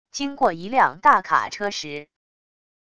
经过一辆大卡车时wav音频